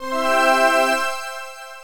level_completed.wav